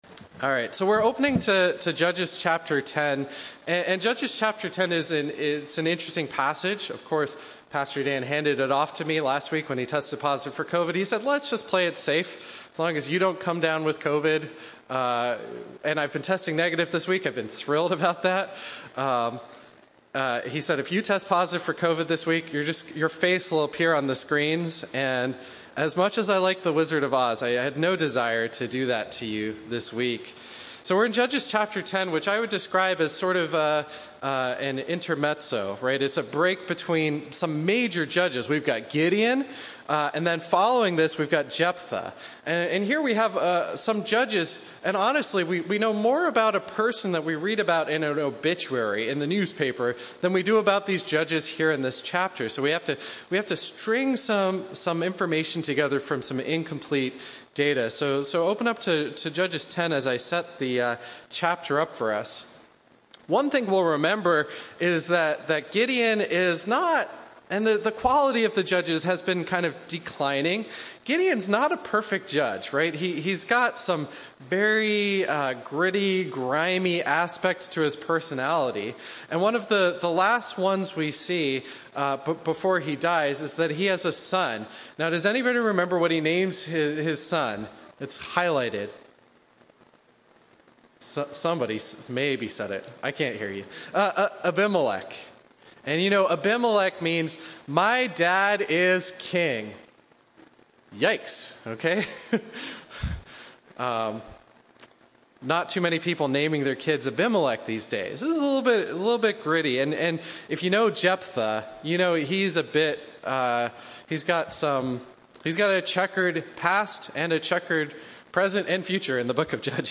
A message from the series "Judges."